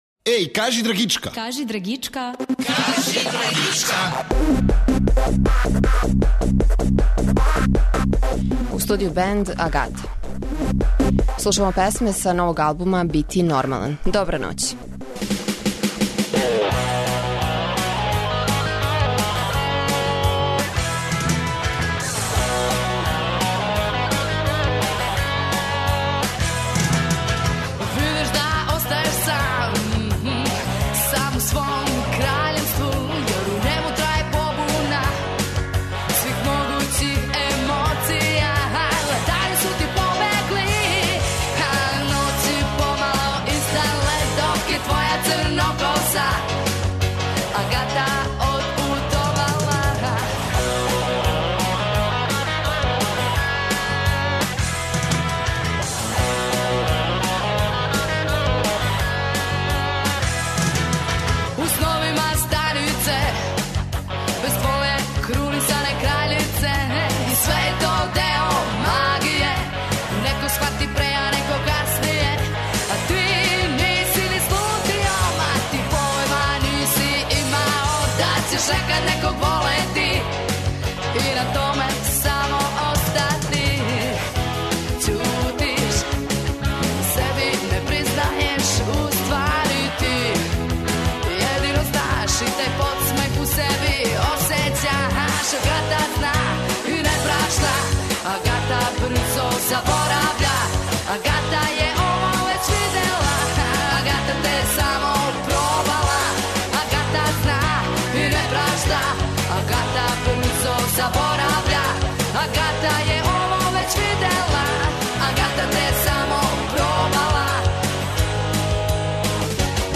У студију је бенд Агата.